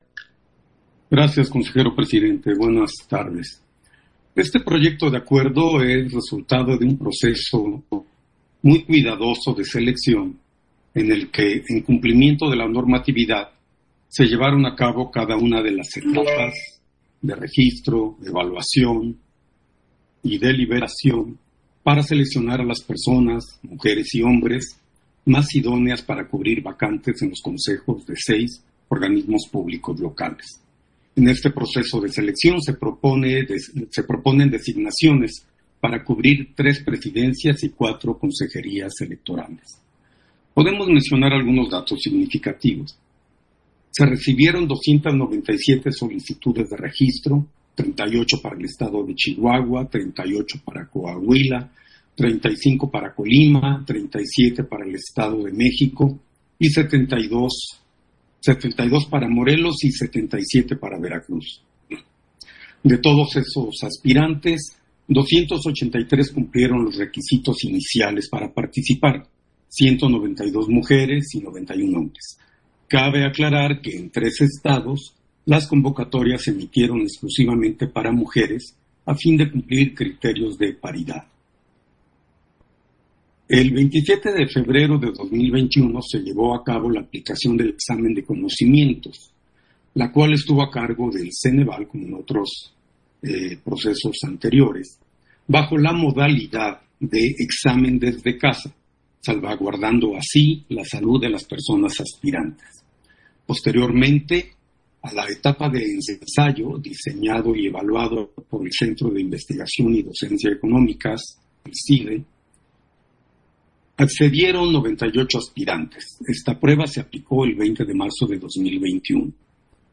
Intervención de Jaime Rivera , en el punto 9 de la Sesión Extraordinaria, en la que se aprueba la designación de Presidencias, Consejeras y Consejeros de OPL